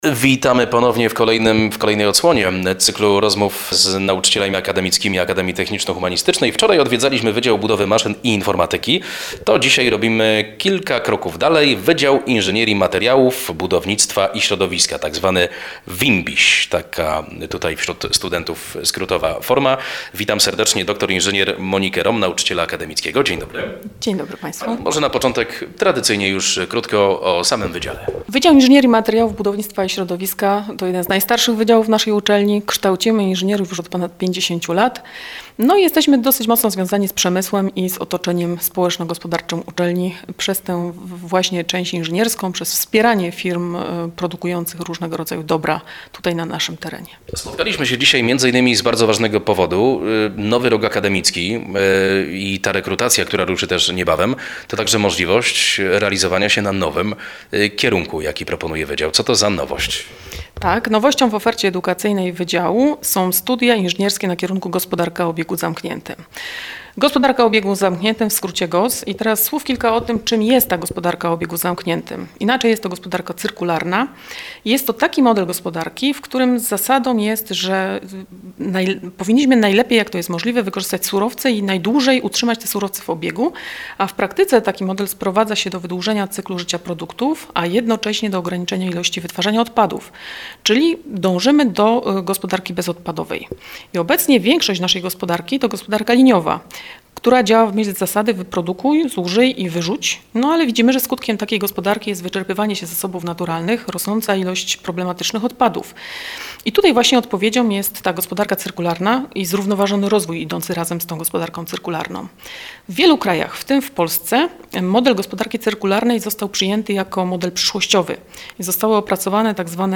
Poniżej załączamy wywiad.